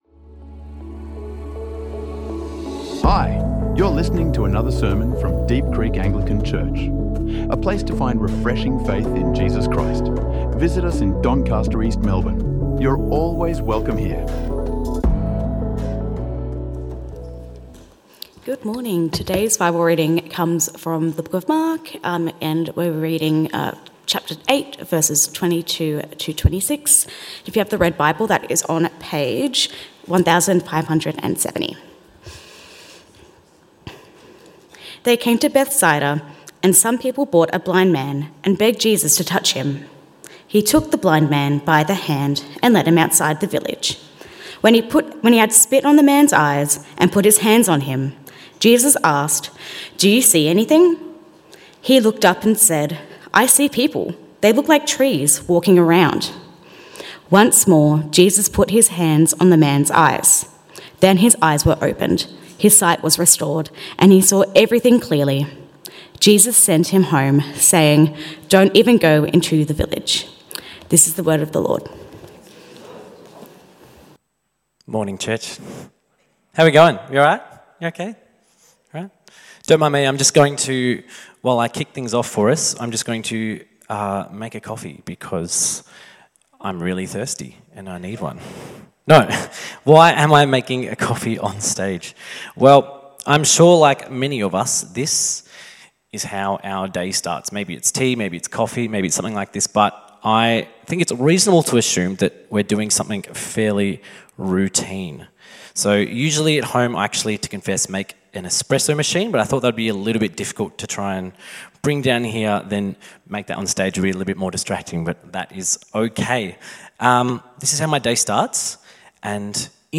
Pursuing God’s Good Purposes | Sermons | Deep Creek Anglican Church